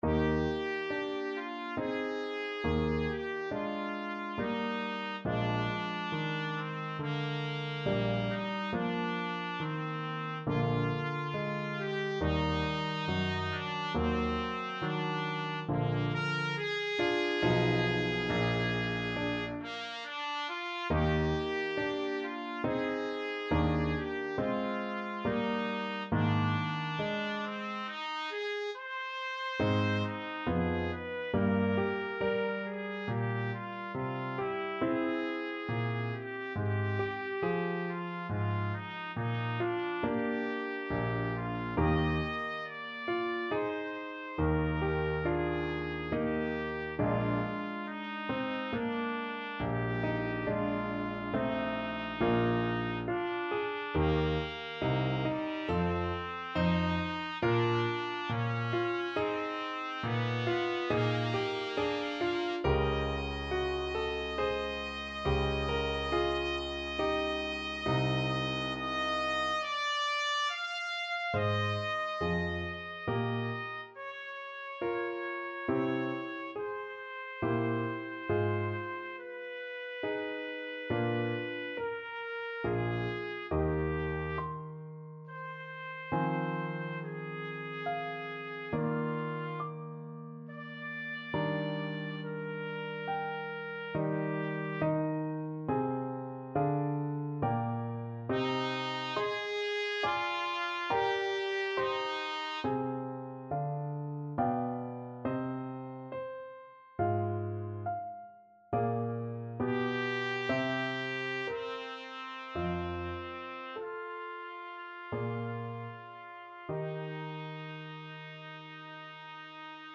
Classical Brahms, Johannes Piano Concerto No.2, Op. 83, Slow Movement Main Theme Trumpet version
Eb major (Sounding Pitch) F major (Trumpet in Bb) (View more Eb major Music for Trumpet )
6/4 (View more 6/4 Music)
Andante =c.84 =69
Classical (View more Classical Trumpet Music)
brahms_pno_con_2_3rd_TPT.mp3